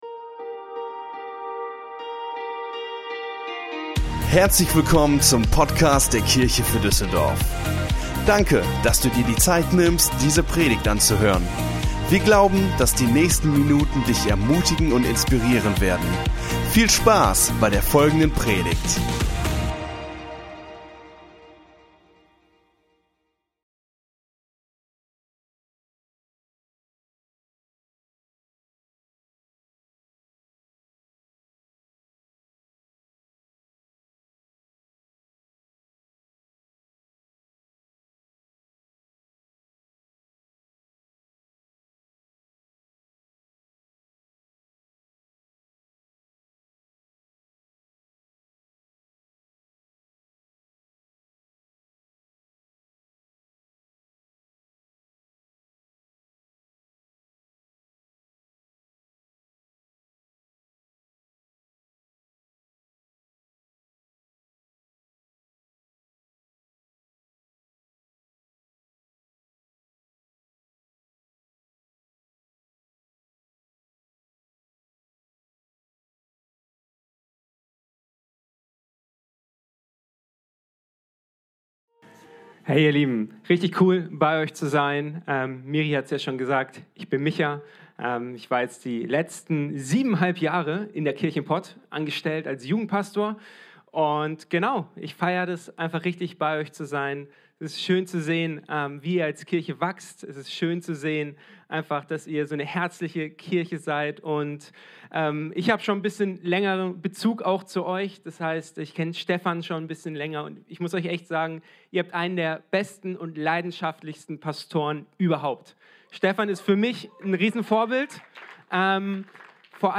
Unsere Predigt vom 22.01.23 Predigtserie: Pray First Teil 2 Folge direkt herunterladen